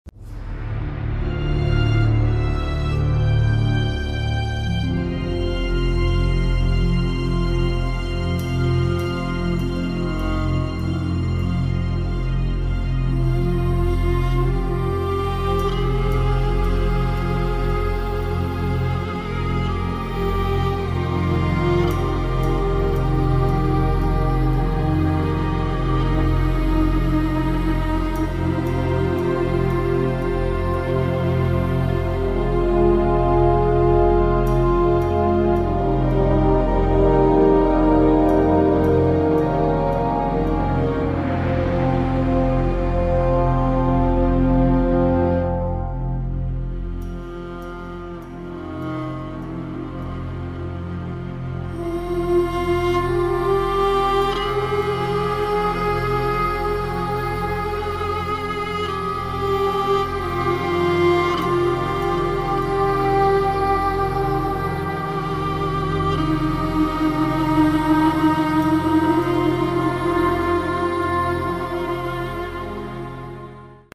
... aber auch was für ruhige Gemüter ist dabei.